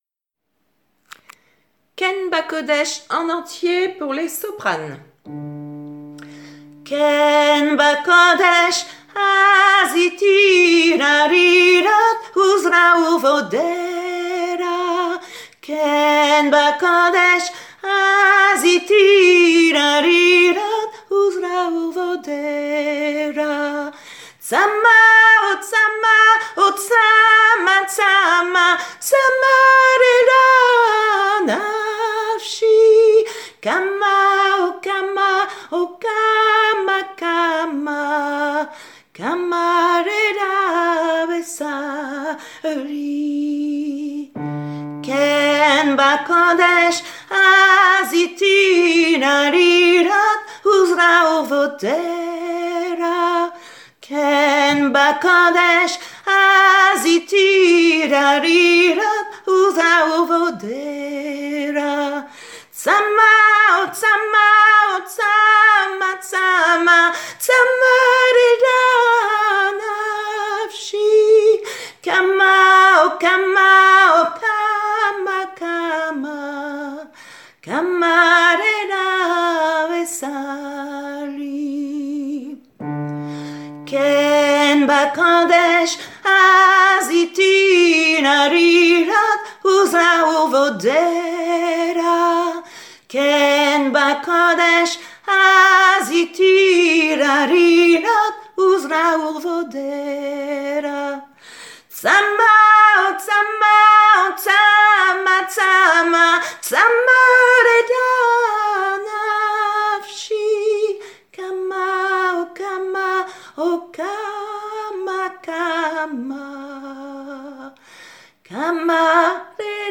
kenbakodesh_sop_en_entier-2.mp3